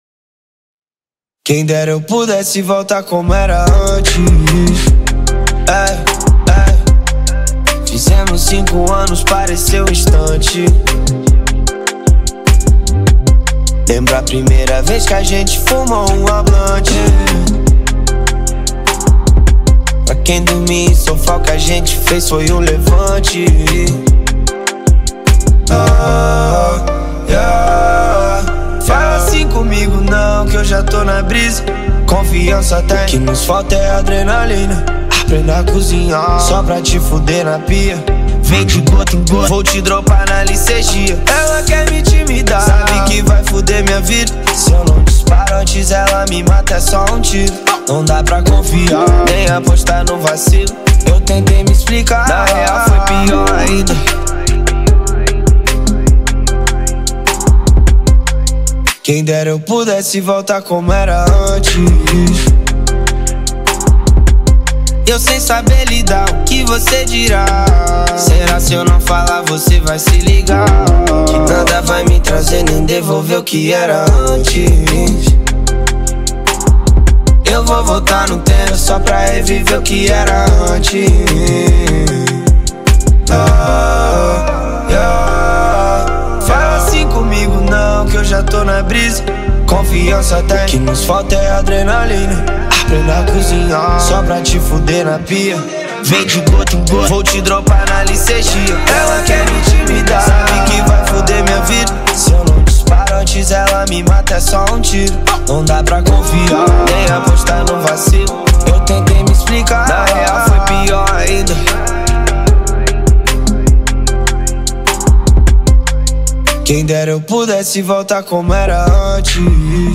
2024-04-08 19:06:14 Gênero: Trap Views